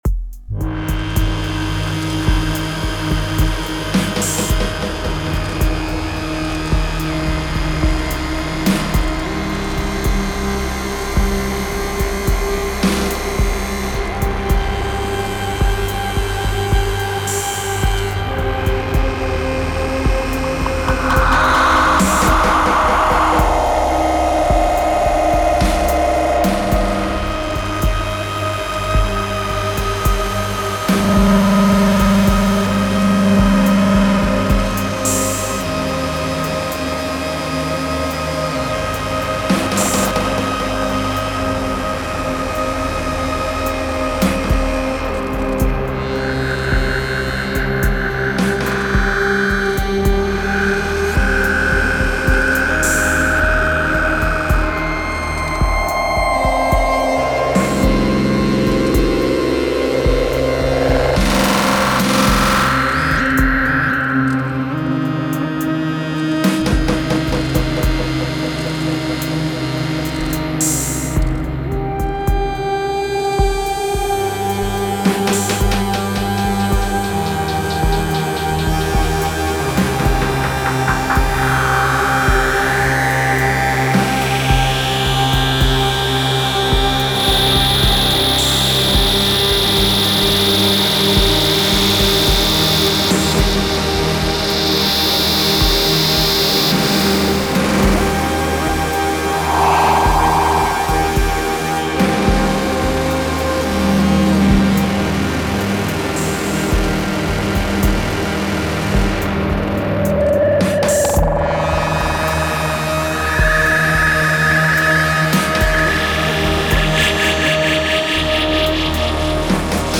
J'ai posé un beat dessus (une 808) avec une impro sur un delay (ohmboyz) posé au cul des snares.
Au tap tempo je suis tombé sur du 54 bpm (ou 108) et ça a l'air de coller.